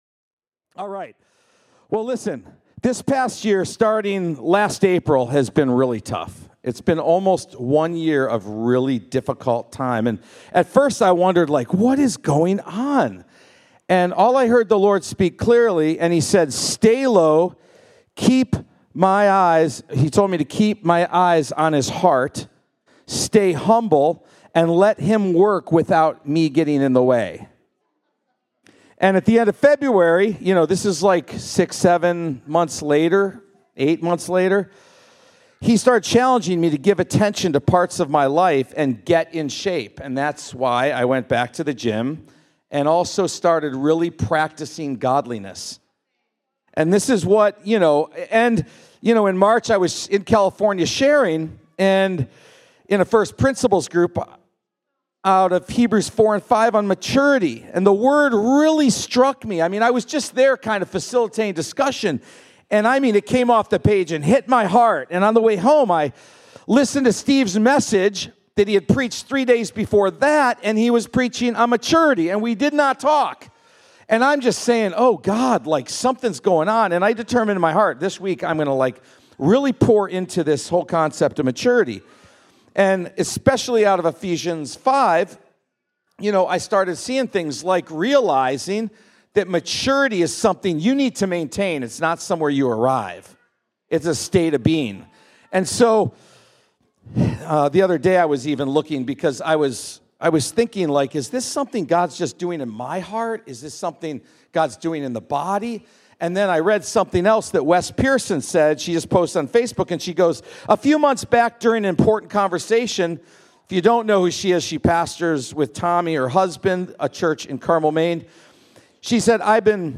3.30.25-Sunday-Service.mp3